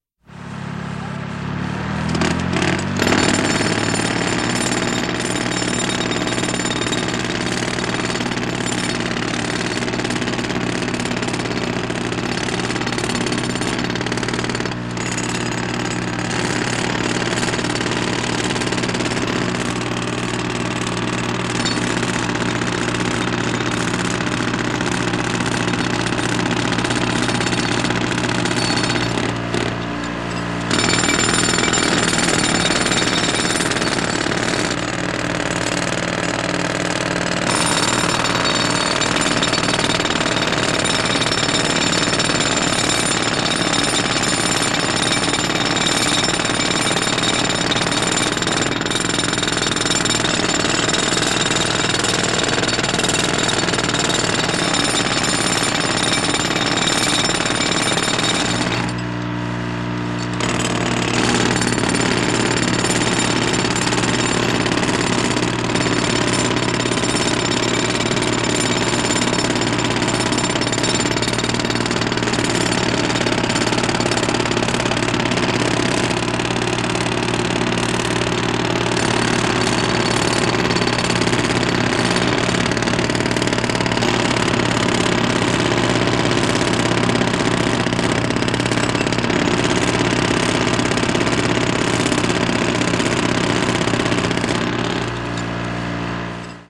sound-concrete-drilling